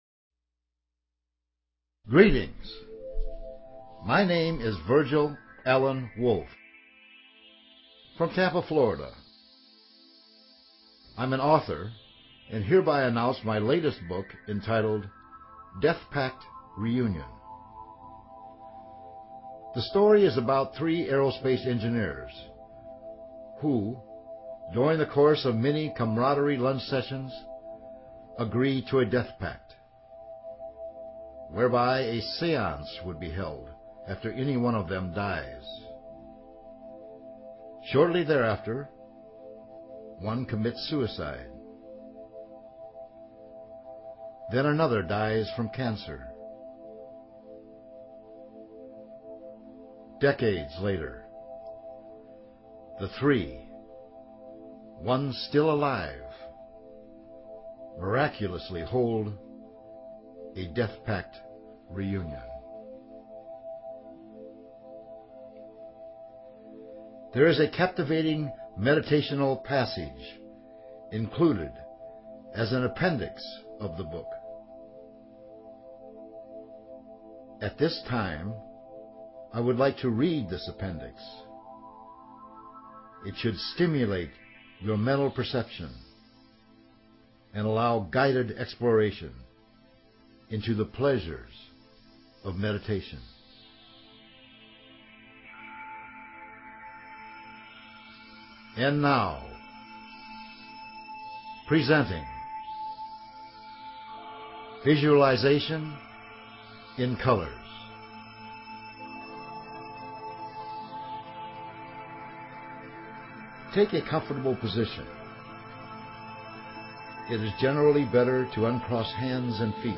Talk Show Episode, Audio Podcast, Wildcard_Fridays and Courtesy of BBS Radio on , show guests , about , categorized as